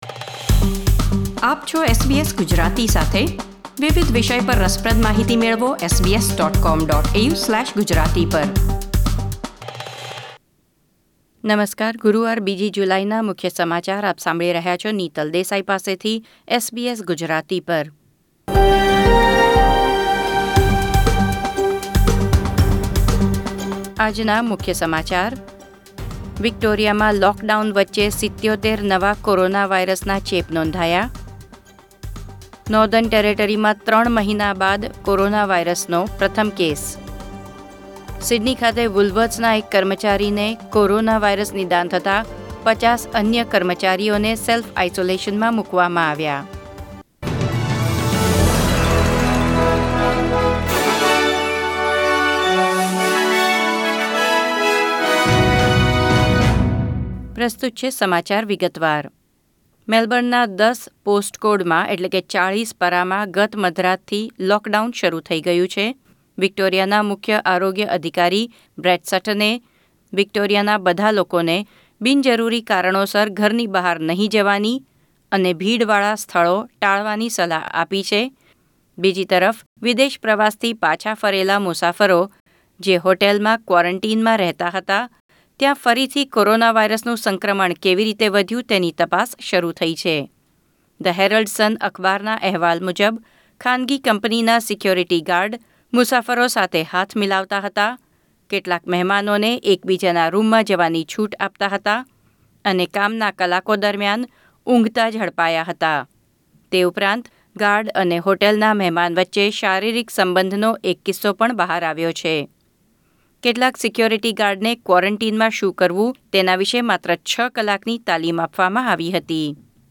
SBS Gujarati News Bulletin 2 July 2020